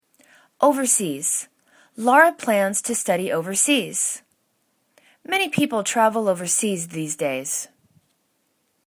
o.ver.seas    /o:vәr'se:z/    adv